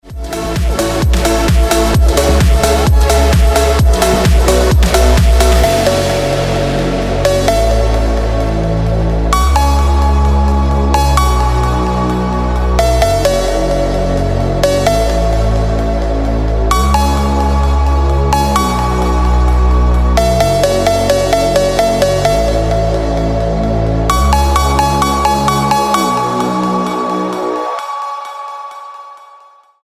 Phonk Music